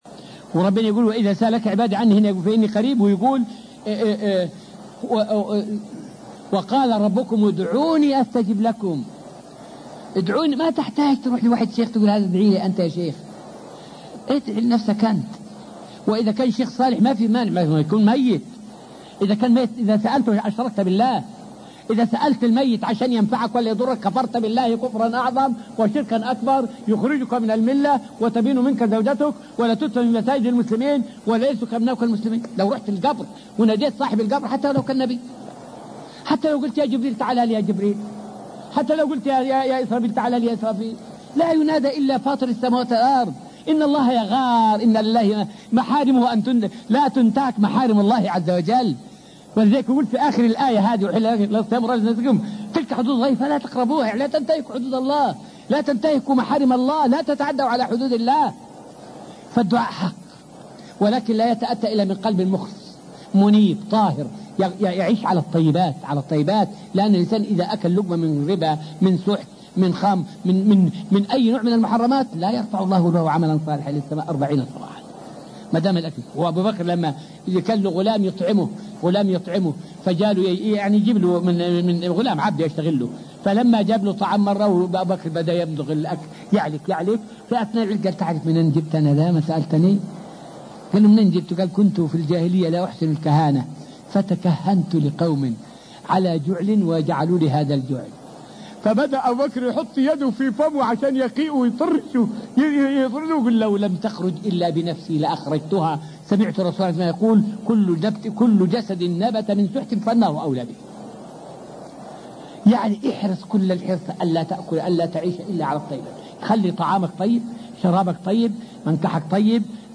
فائدة من الدرس الرابع والعشرون من دروس تفسير سورة البقرة والتي ألقيت في المسجد النبوي الشريف حول أكل الحرام من موانع قبول العمل.